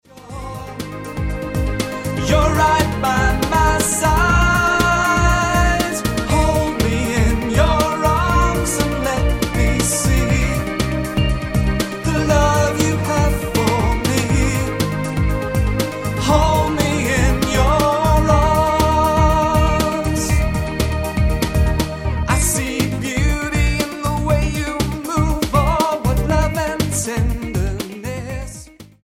Style: Rock Approach: Praise & Worship